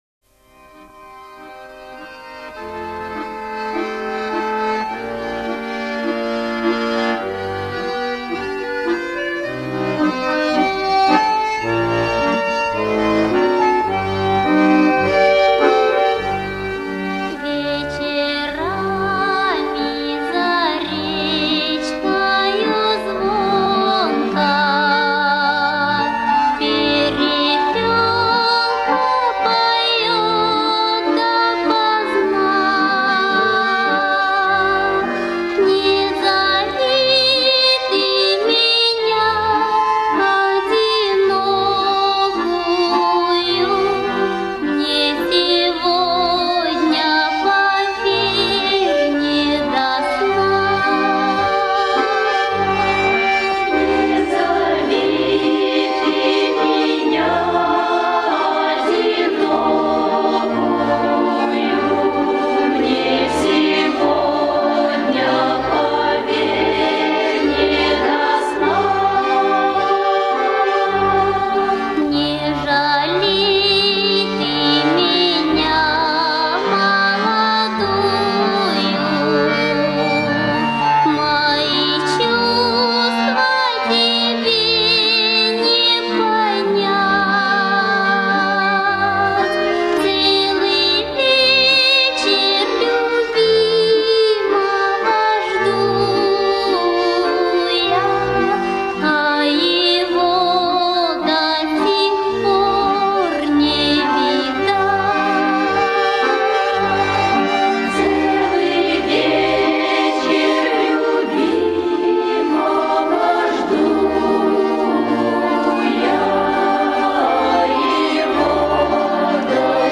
На хоровое исполнение ориентироваться, конечно, сложновато.